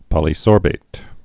(pŏlē-sôrbāt)